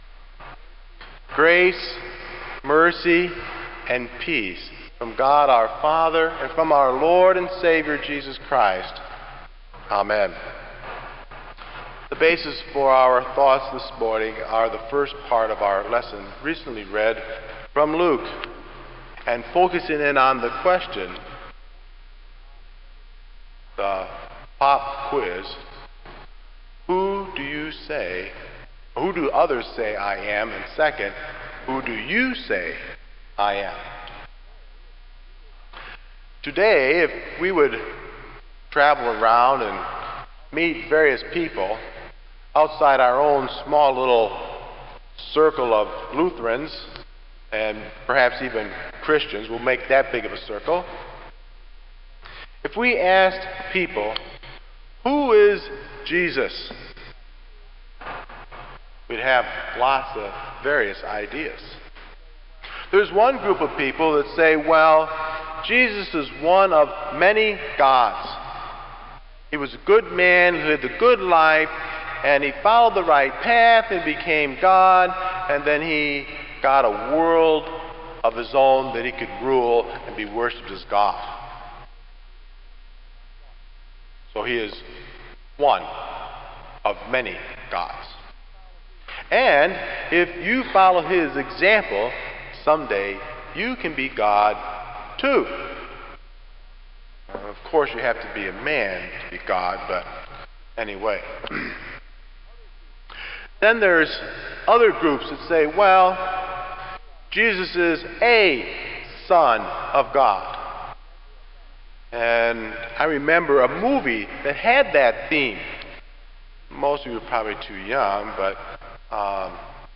Kramer Chapel Sermon - July 05, 2002